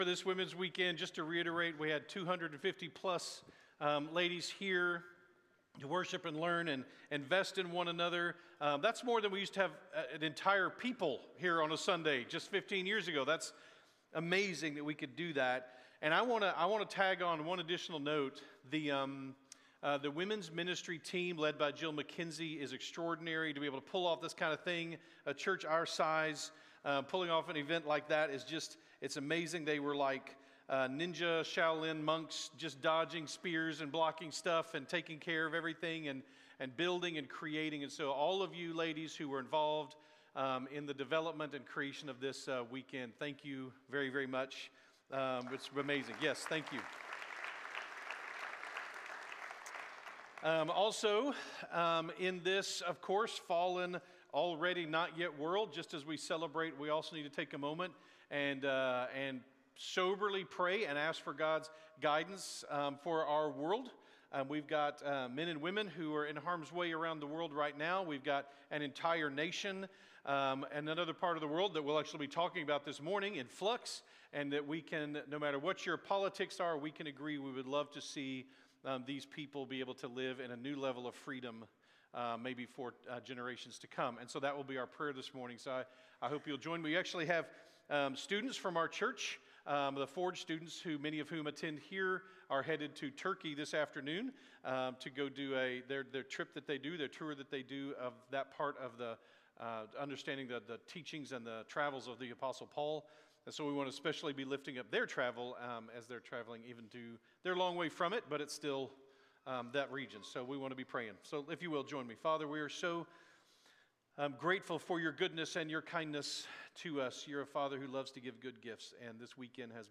March-1-2026-Sunday-Morning.mp3